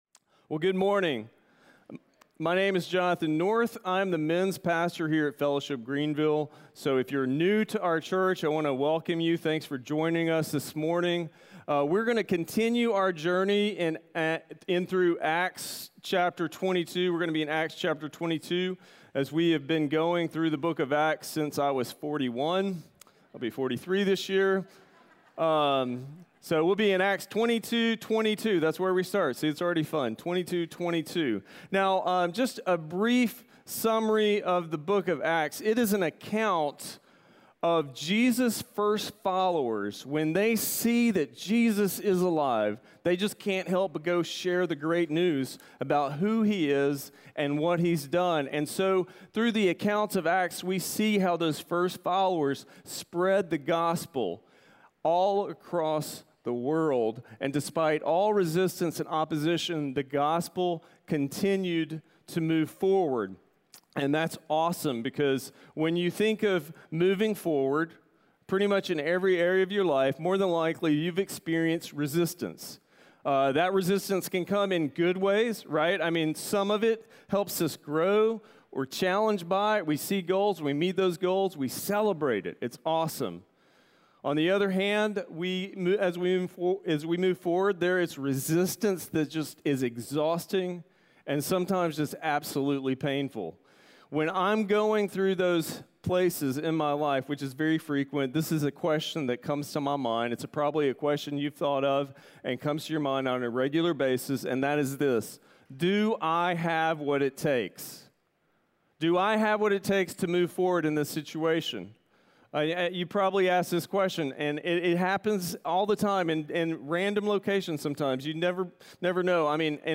Audio Sermon Notes (PDF) Ask a Question *We are a church located in Greenville, South Carolina.